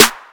God Hour Snare 2.wav